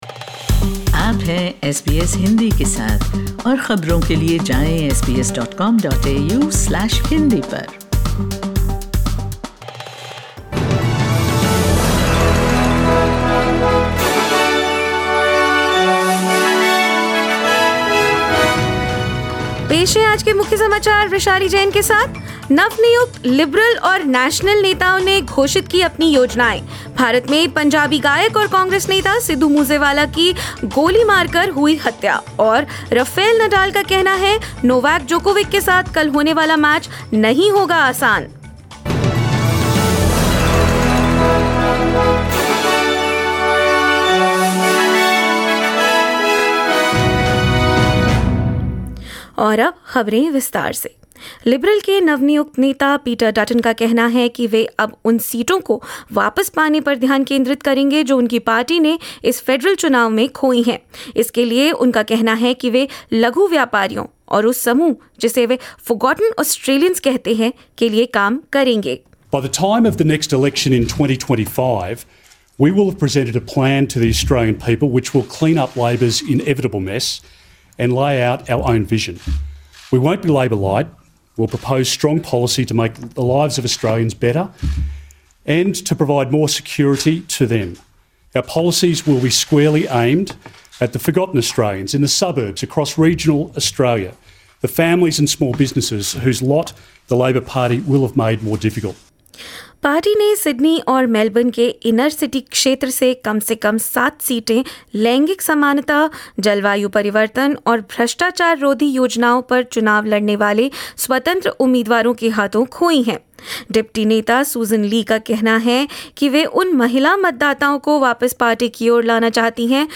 In this latest SBS Hindi bulletin: The newly elected leaders of the Liberal and Nationals parties outline their priorities; Indian singer Sidhu Moose Wala shot dead in a suspected gang war; Rafael Nadal says he is anticipating a tough match against Novak Djokovic in the French Open quarterfinals, and more.